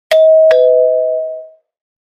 Classic Apartment Doorbell Sound Effect
Description: Classic apartment doorbell sound effect. Clean, simple and familiar chime typically heard in residential buildings and flats.
Ding dong doorbell tone.
Classic-apartment-doorbell-sound-effect.mp3